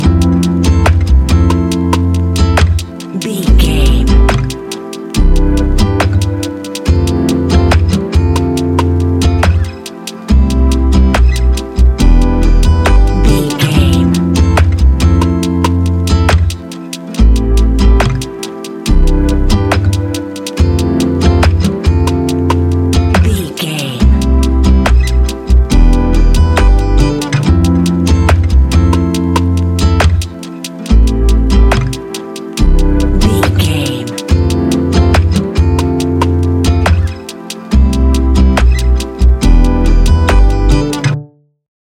Ionian/Major
laid back
Lounge
sparse
new age
chilled electronica
ambient
atmospheric
morphing